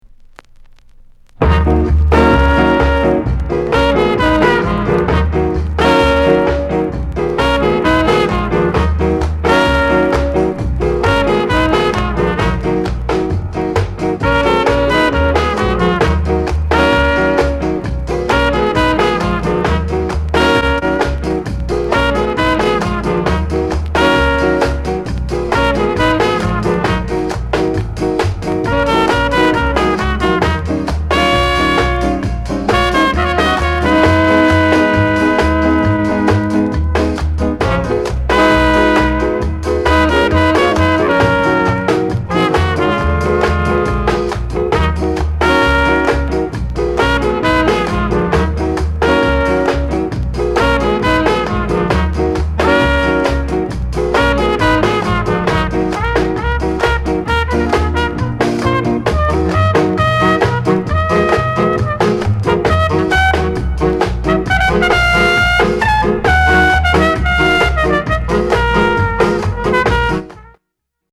WICKED SKA INST